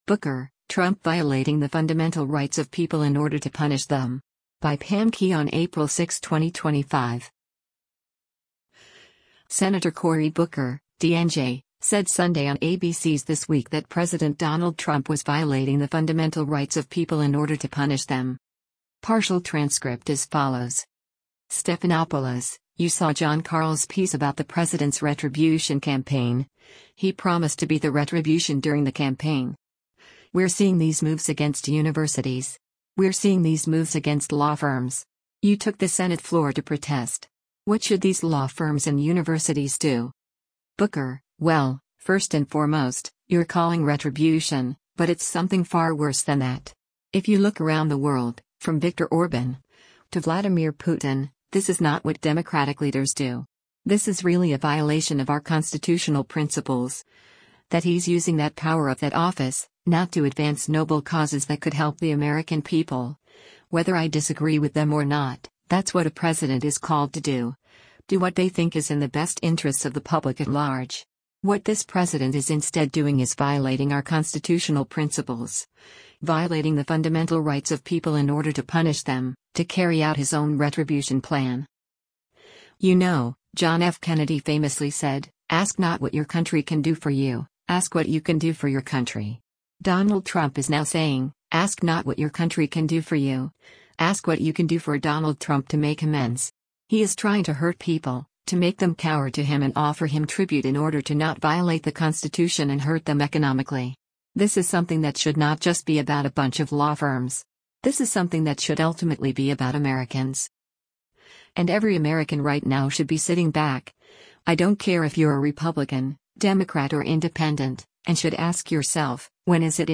Senator Cory Booker (D-NJ) said Sunday on ABC’s “This Week” that President Donald Trump was “violating the fundamental rights of people in order to punish them.”